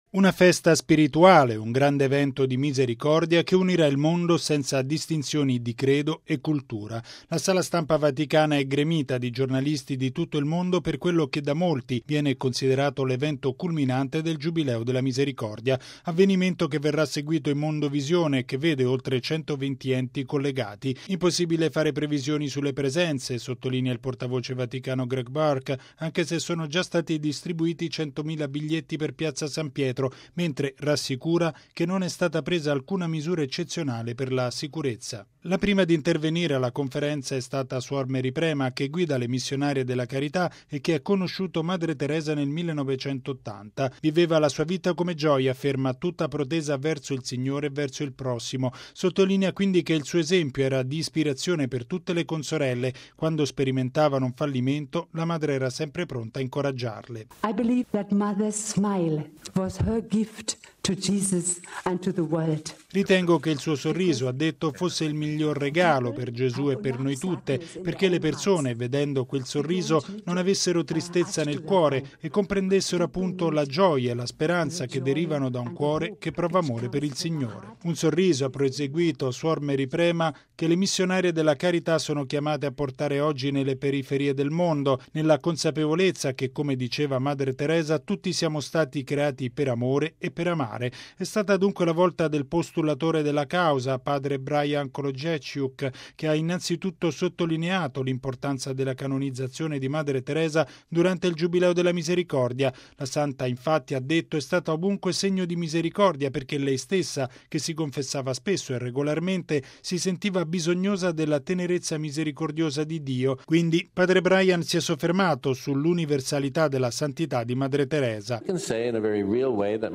Una festa spirituale, un grande evento di misericordia che unirà il mondo senza distinzioni di credo e cultura. La Sala Stampa vaticana è gremita di giornalisti di tutto il mondo per quello che, da molti, viene considerato l’evento culminante del Giubileo della Misericordia.